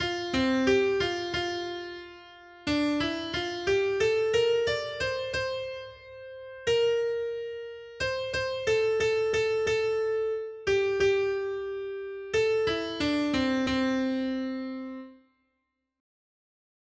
Noty Štítky, zpěvníky ol5.pdf responsoriální žalm Žaltář (Olejník) 5 Ž 25, 1-22 Skrýt akordy R: Pane, Bože můj, k tobě pozvedám svou duši. 1.